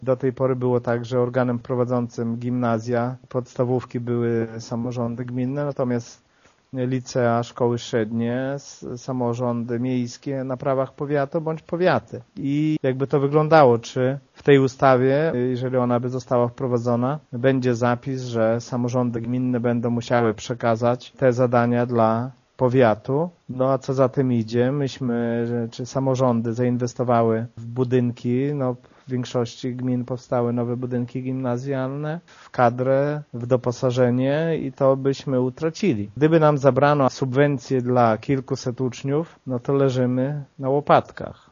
Jedna z propozycji ma dotyczyć łączenia w zespoły gimnazjów i szkół ponadgimnazjalnych. Według wójta gminy Mircze pomysł ten jest co najmniej dziwny: